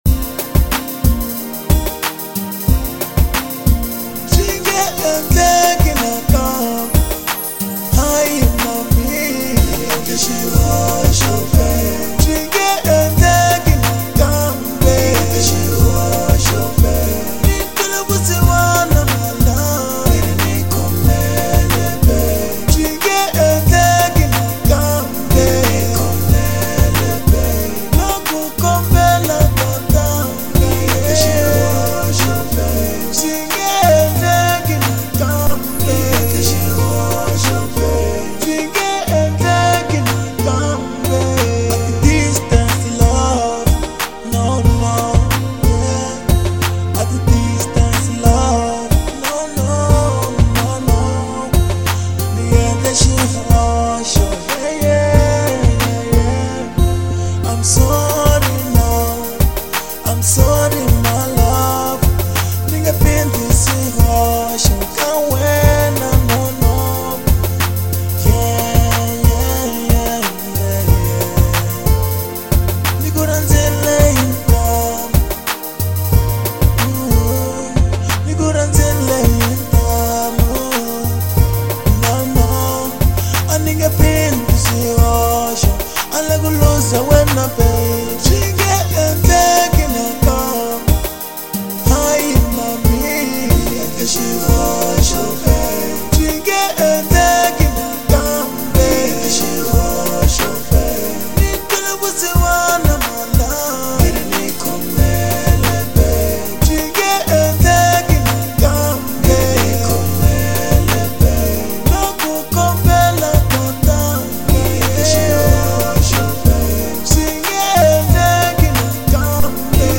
03:10 Genre : RnB Size